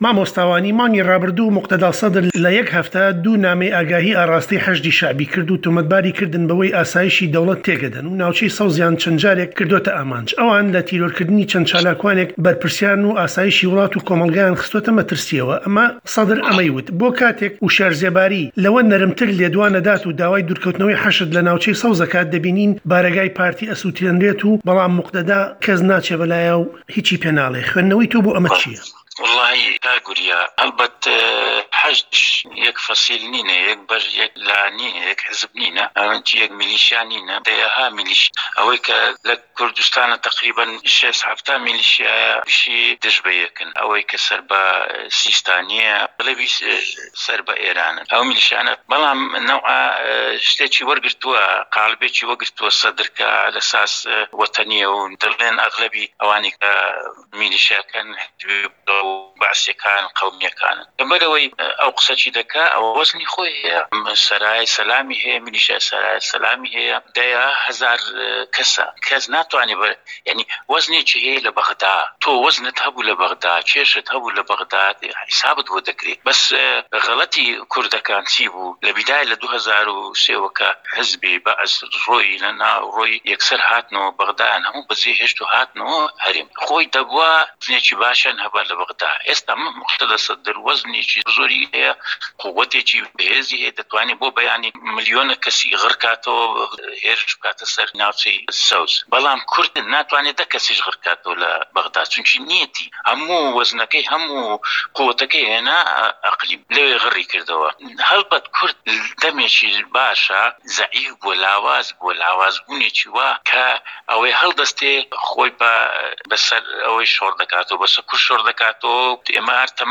گفتووگۆ